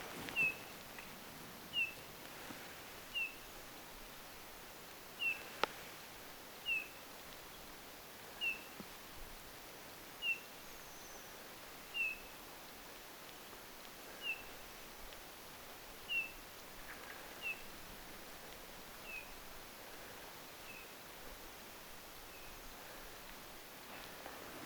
hienosti äänelevä punatulkku
Poikkeuksellisen sointuvaa ääntelyä?
hienosti_aanteleva_punatulkku.mp3